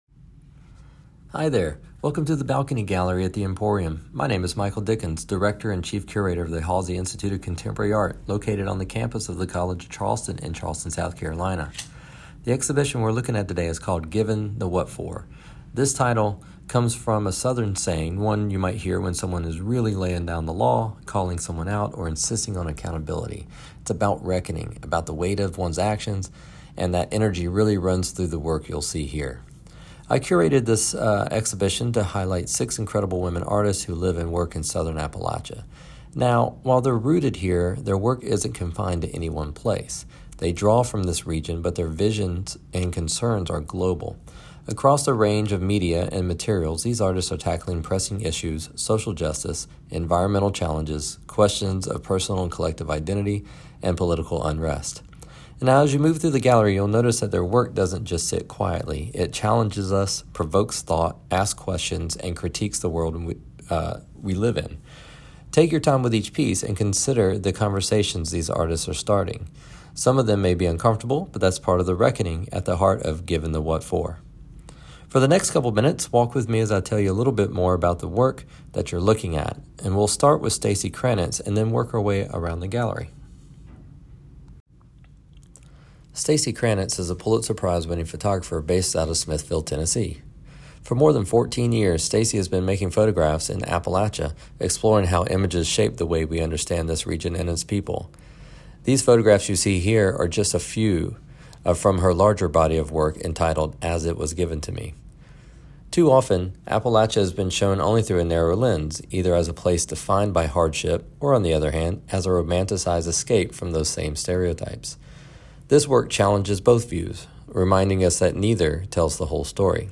Enjoy a welcome statement from the curator!